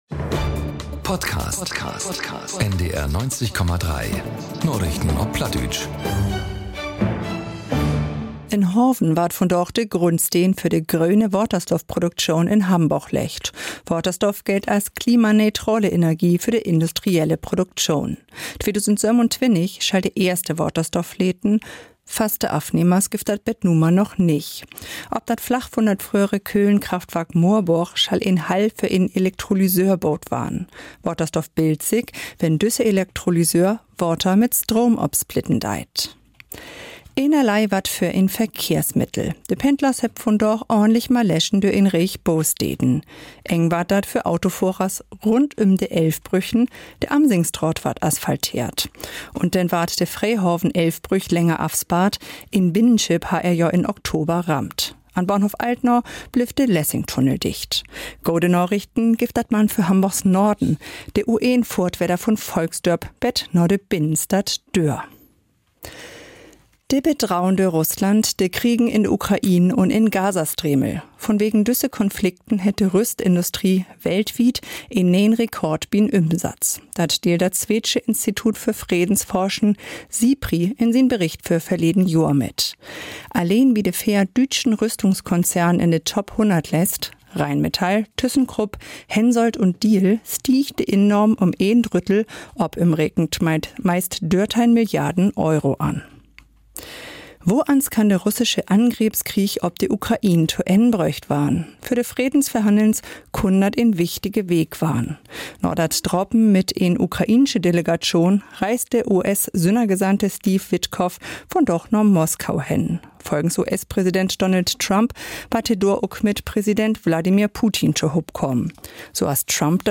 Narichten op Platt 01.12.2025 ~ Narichten op Platt - Plattdeutsche Nachrichten Podcast